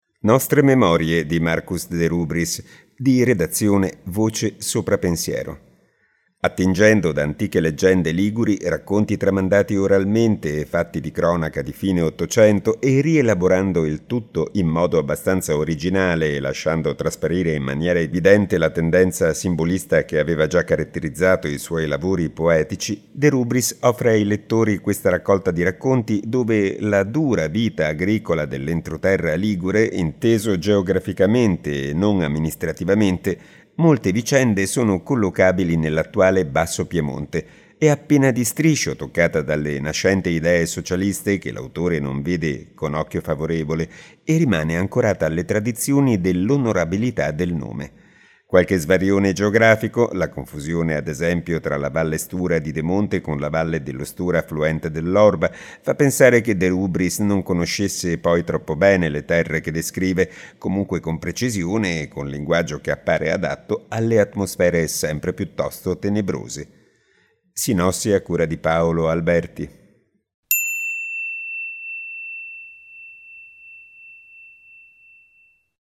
Dall’incipit del libro: